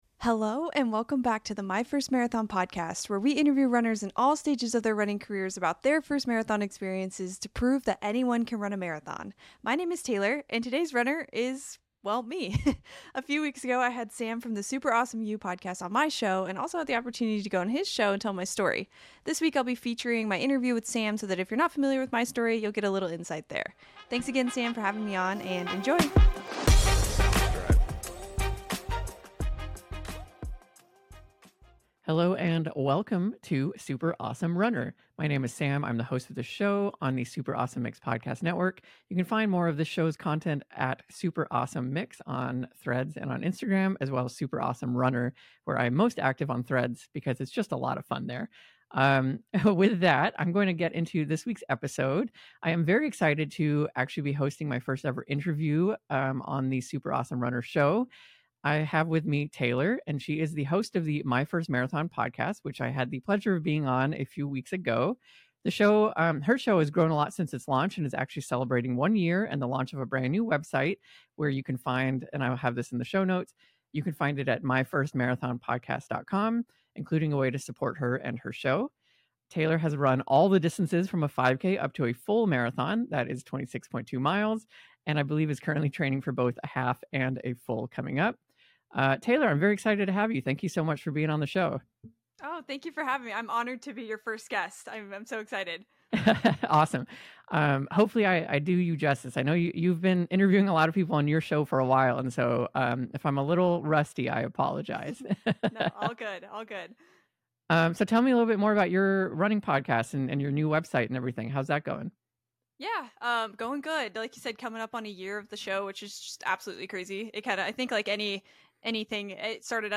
[Podcast Swap] Telling MY Running Story - My Interview with the Super Awesome You Podcast